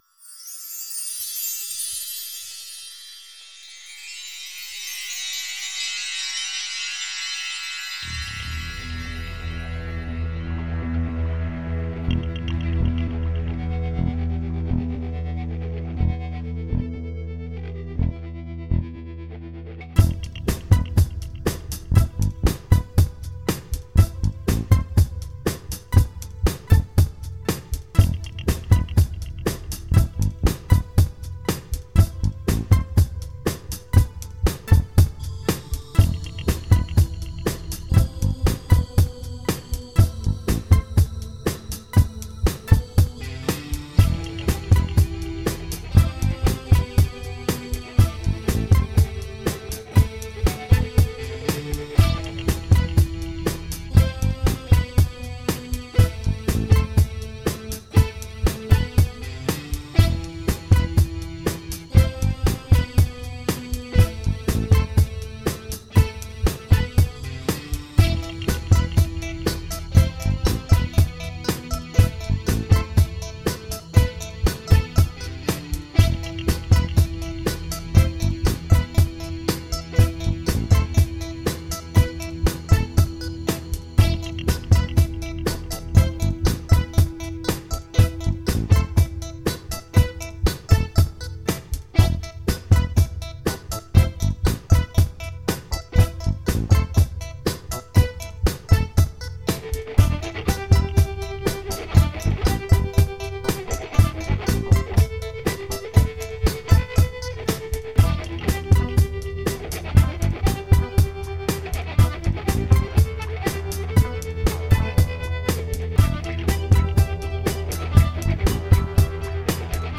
soundtrack für einen auftragsfilm. teilweise recht dilletantisch, zugegeben. aber alles selber mit garage-band gemacht ausser den drums, die aus einem midi-sample stammen: bass, gitarren, und keys